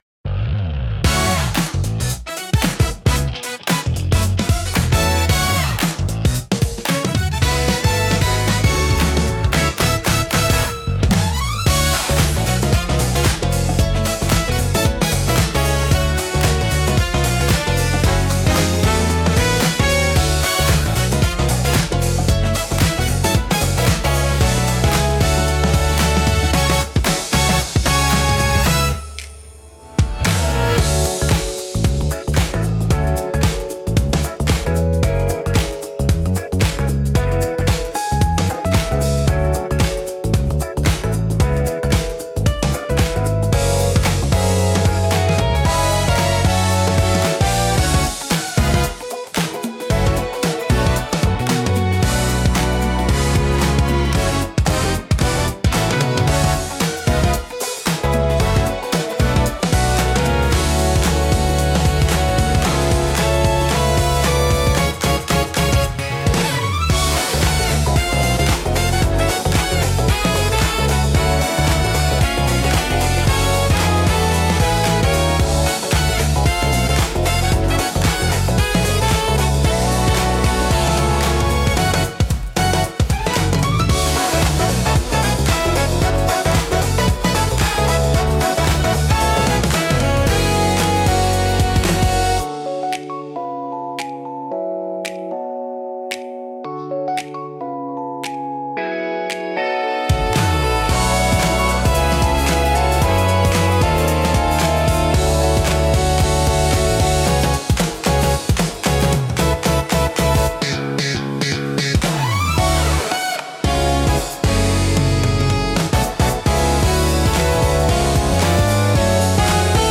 聴く人に洗練された印象を与えつつ、リラックスと活気のバランスを巧みに表現します。